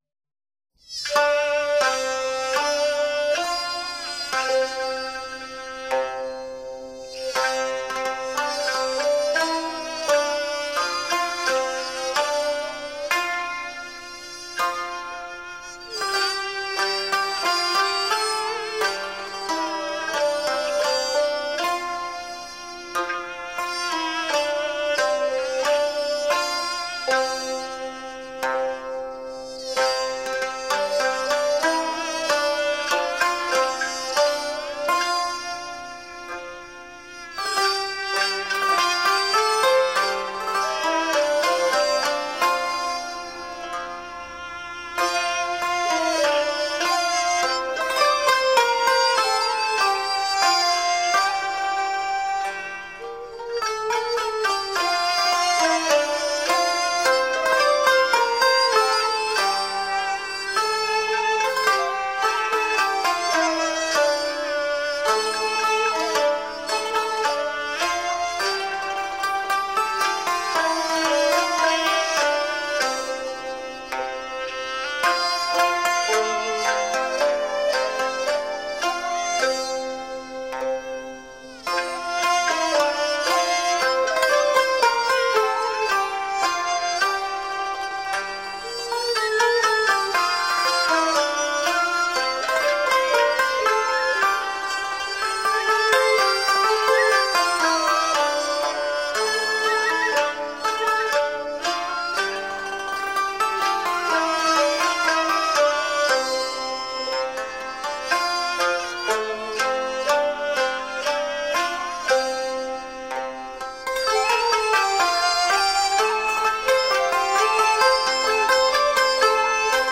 潮州音乐简介